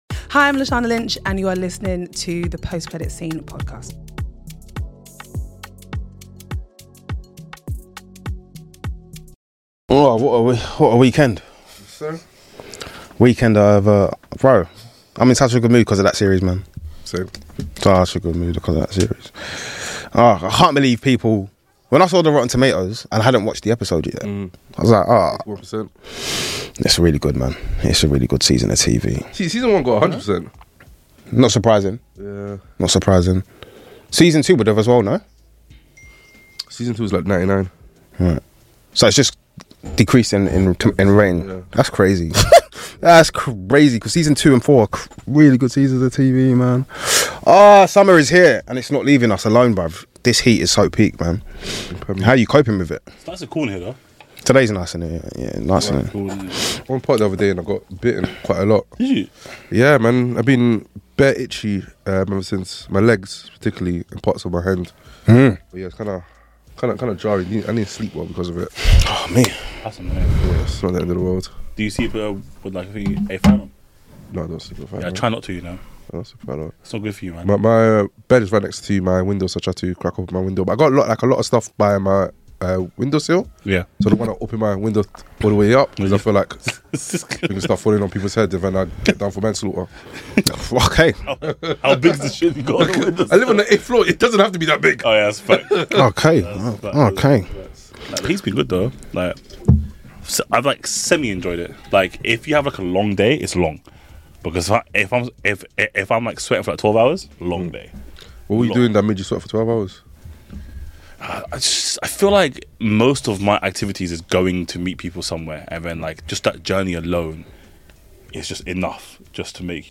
This podcast is the ultimate destination for film & tv lovers, diving deep into the world of cinema with thought-provoking discussions, exclusive interviews, and behind-the-scenes insights.